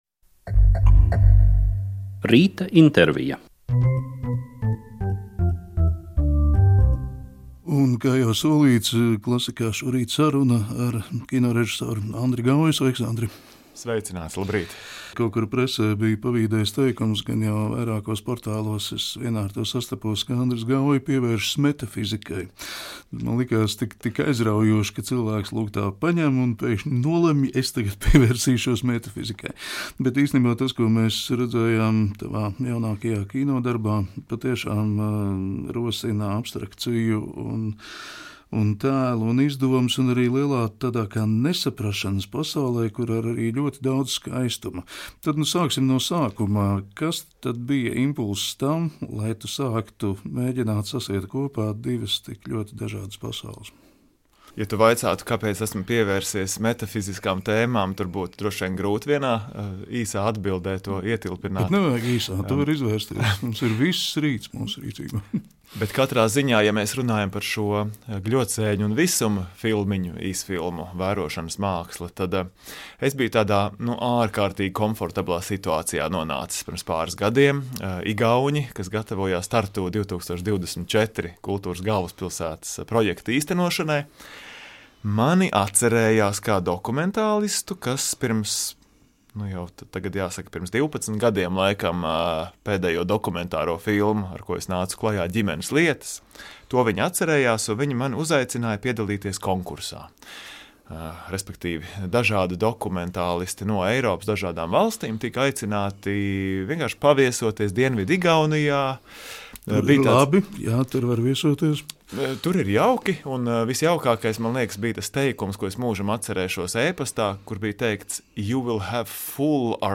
Sarunājas